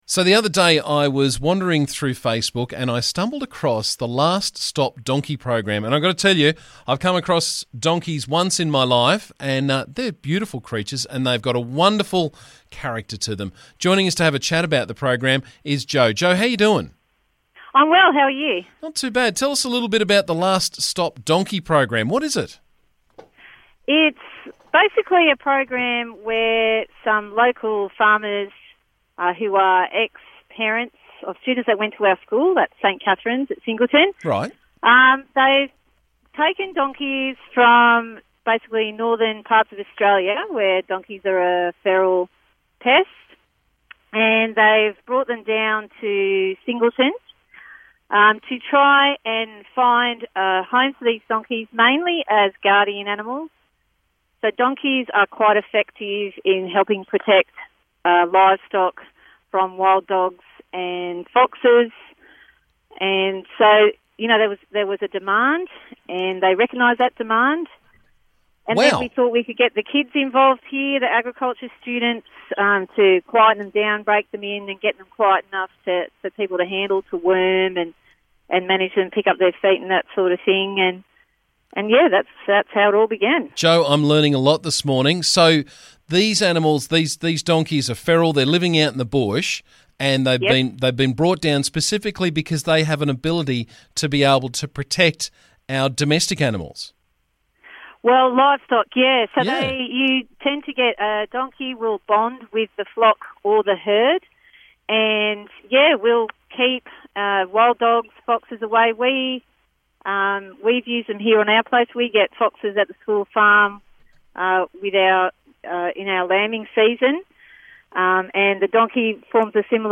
We learn some amazing things on the breakfast show and none more so than how donkeys protect livestock.